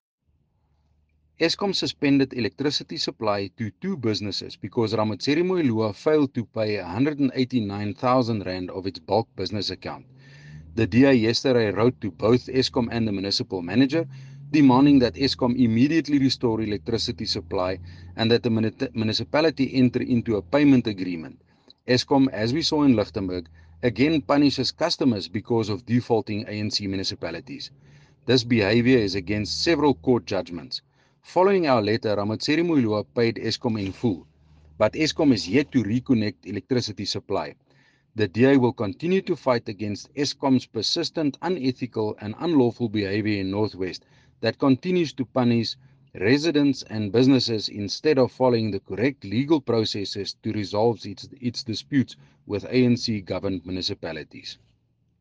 Note to Broadcasters: Please find linked soundbites in
English and Afrikaans by Cllr Cornel Dreyer.
Cllr-Cornel-Dreyer-Eskom-Eng.mp3